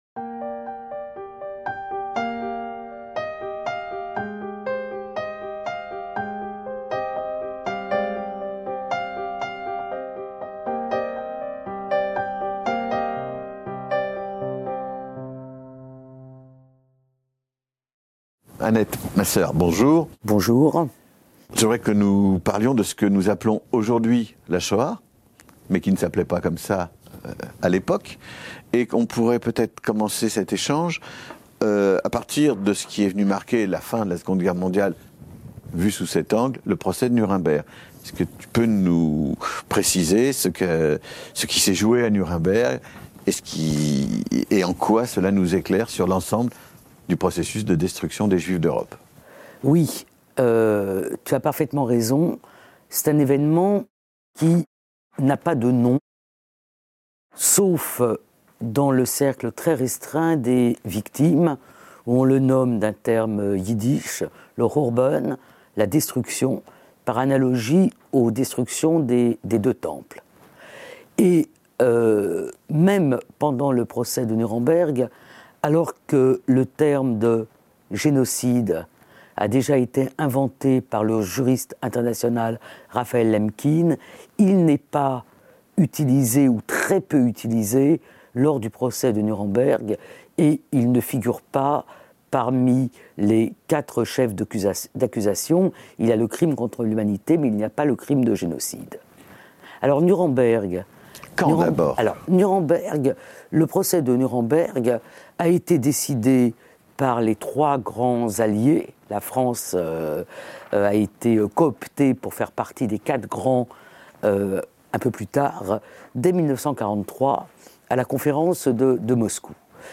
La Shoah - Un entretien avec Annette Wieviorka | Canal U